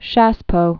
(shăspō)